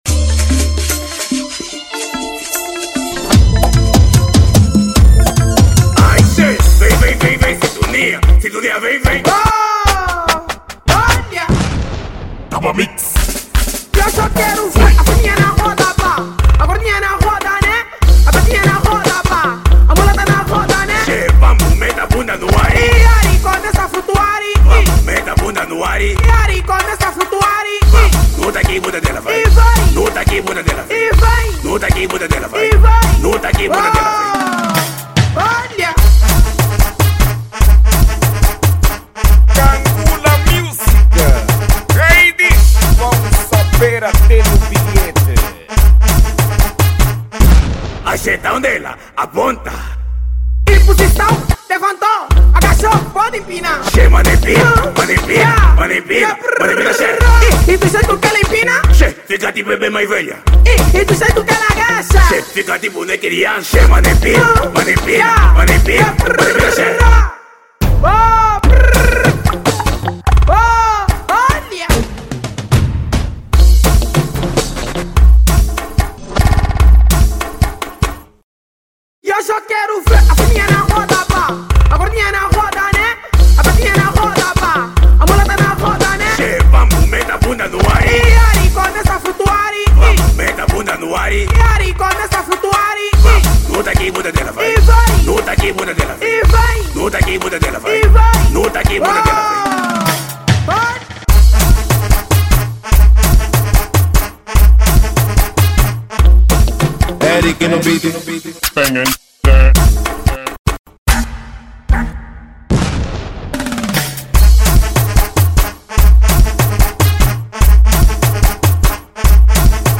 | Afro house